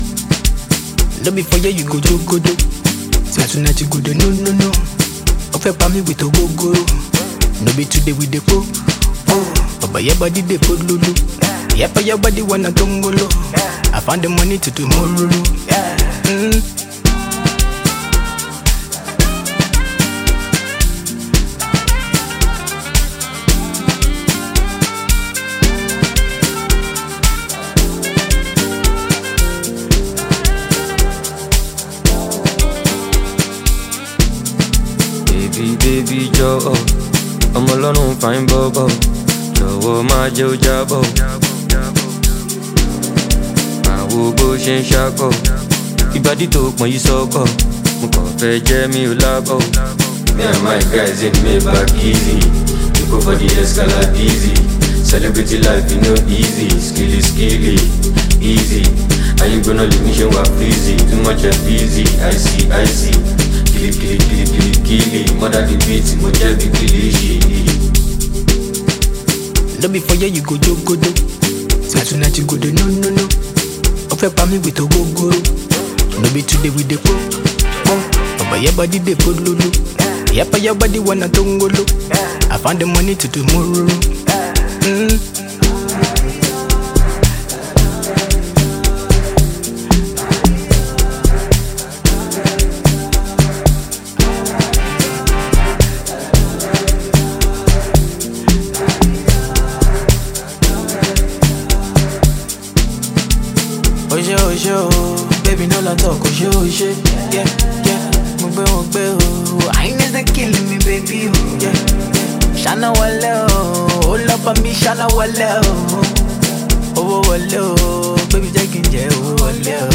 Nigeria/International Afrobeat Grammy award winning Singer